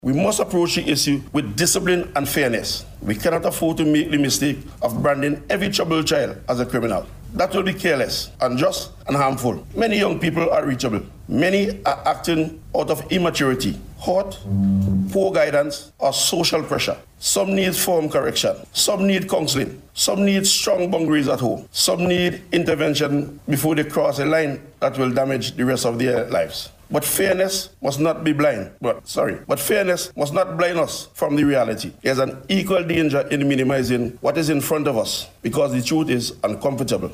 Delivering remarks at a National Consultation on School Violence held here this week, ACP Bailey noted that criminal activity cannot be addressed in isolation.